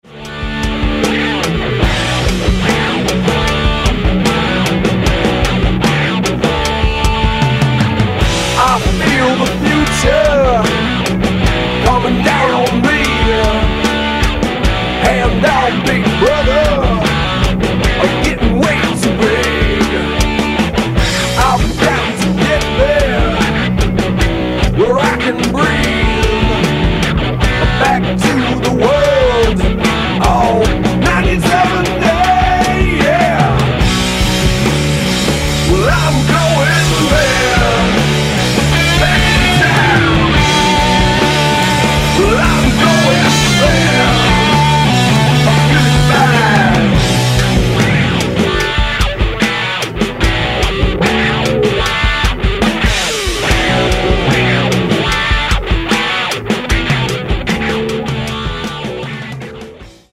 BASS & VOCALS
GUITAR
DRUMS